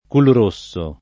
culrosso [ kulr 1SS o ]